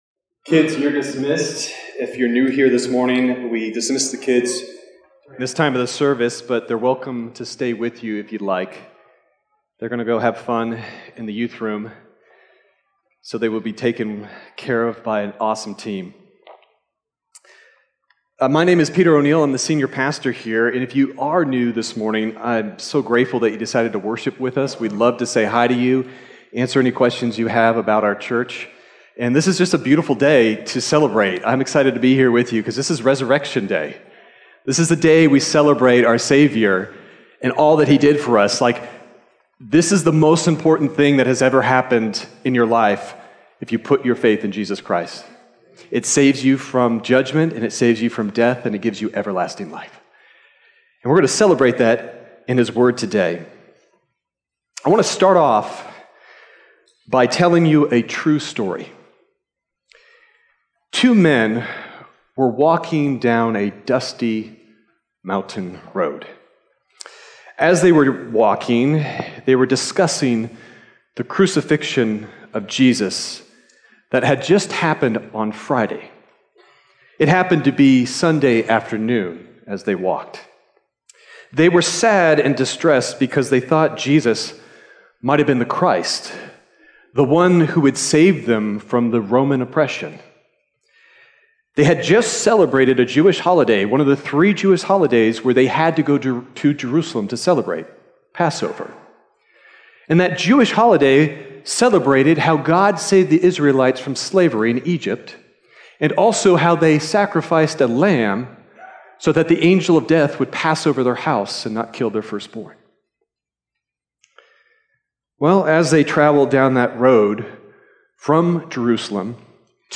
Sermon Detail
March_31st_Sermon_Audio.mp3